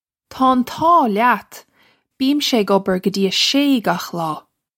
Pronunciation for how to say
Taw un taw lyat! Bee-im-sha ig ubber guh jee uh shay gokh law.
This is an approximate phonetic pronunciation of the phrase.